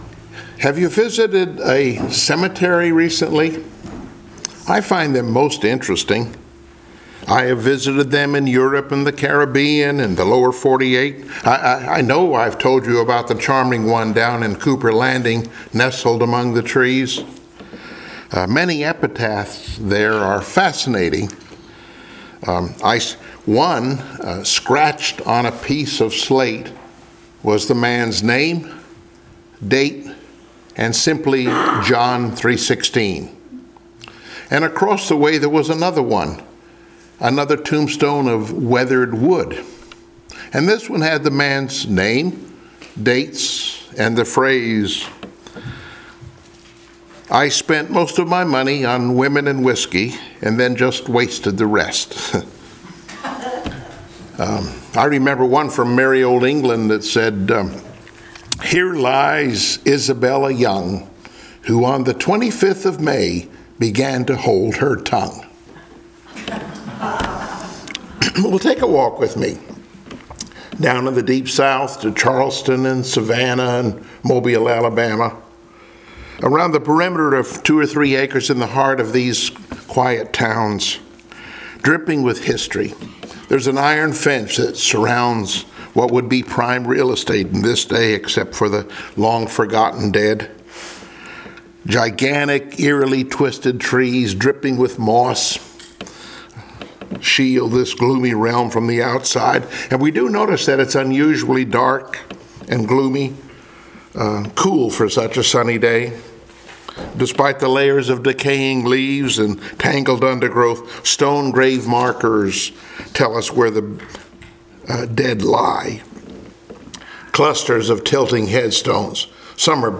9-18 Service Type: Sunday Morning Worship « “Tested But Not Broken” “Then Noah Knew…”